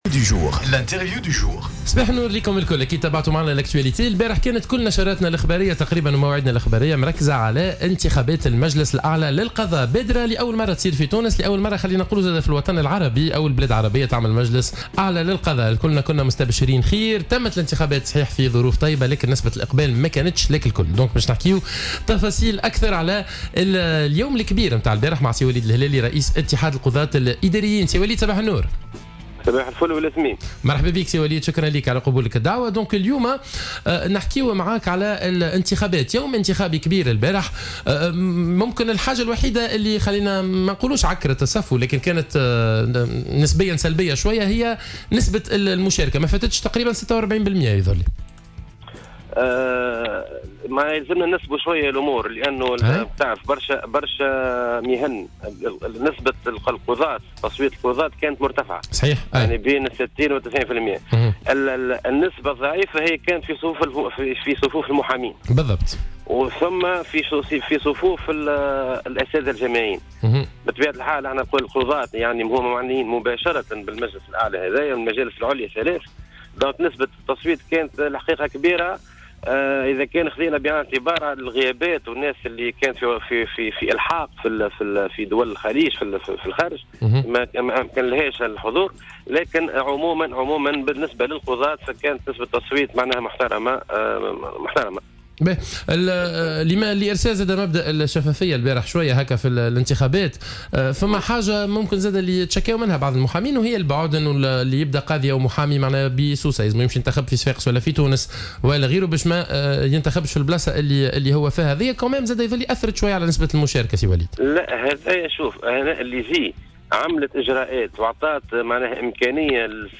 وأوضح في تصريح لـ "الجوهرة اف أم" في برنامج "صباح الورد" أن هذه القوانين ستشمل القضاء العدلي والمالي والإداري وستعمل على تكريس قضاء ناجع وسلطة قضائية حقيقة بعد انتخاب المجلس الأعلى للقضاء. ولفت إلى أهمية تحسين ظروف عمل القضاة المادية، مؤكدا أن السلطة القضائية تتمثل في سلطة الأحكام القضائية من خلال تنفيذها.